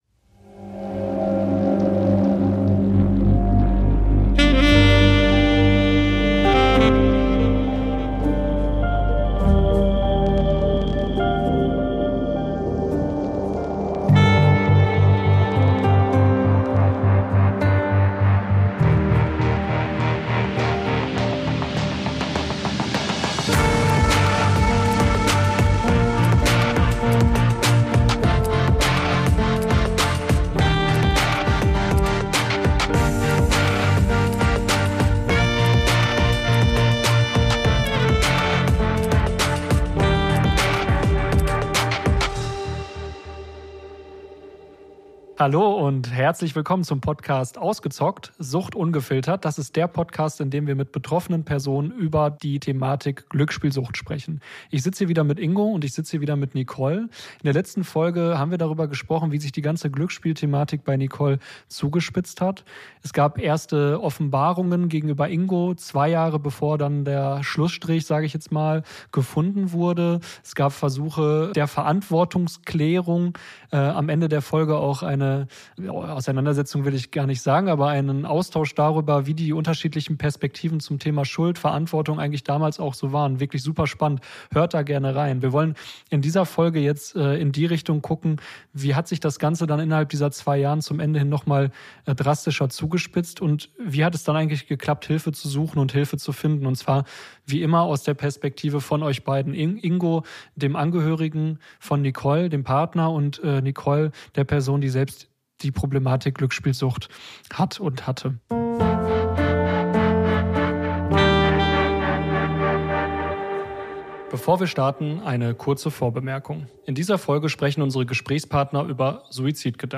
Folge 4: Der Tiefpunkt ~ Ausgezockt: Sucht ungefiltert - Betroffene im Gespräch Podcast